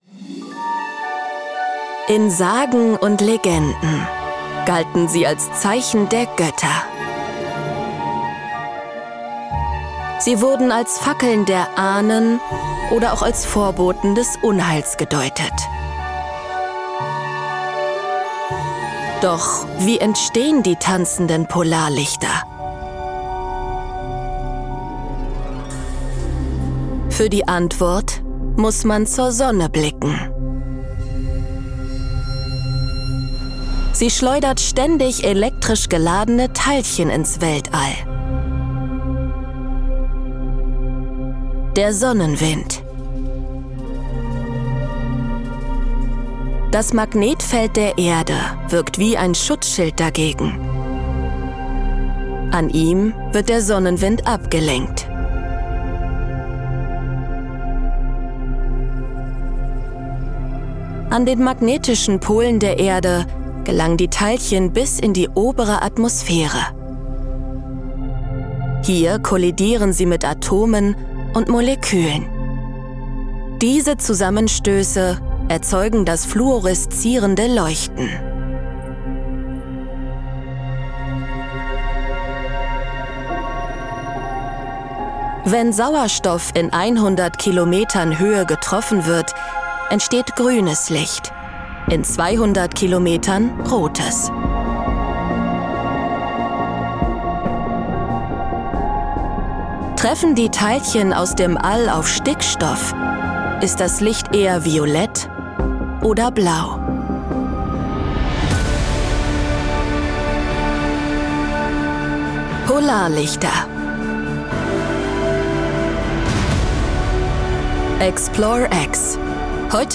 Natuurlijk, Warm, Vriendelijk, Zacht
Explainer